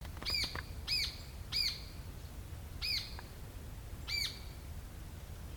Hornero (Furnarius rufus)
Nombre en inglés: Rufous Hornero
Fase de la vida: Adulto
Localidad o área protegida: Reserva Natural del Pilar
Condición: Silvestre
Certeza: Observada, Vocalización Grabada
hornero.mp3